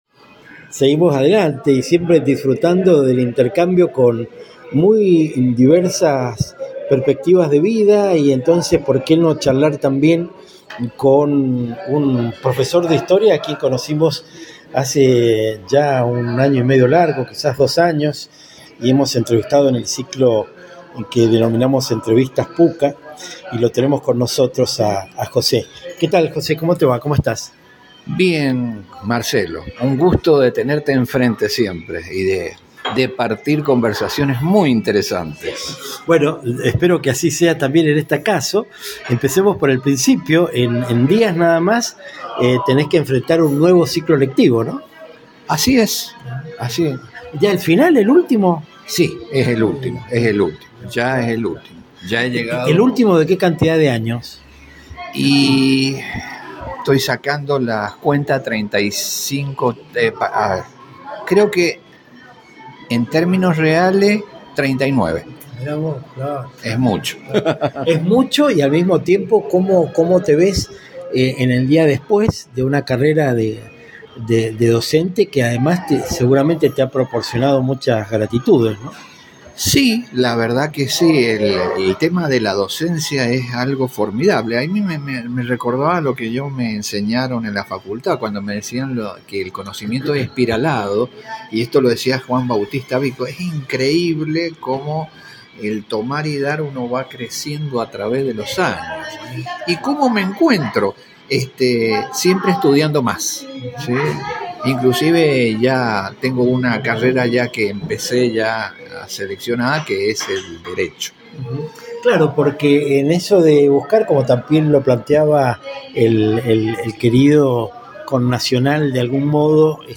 Su forma de hablar, sin estridencias y con un todo ameno, hace que sí o sí te concentrés en lo que te dice.